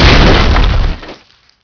grndhit.wav